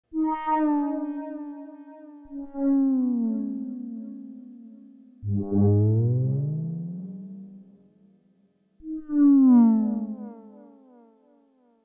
WhaleTalk 2.wav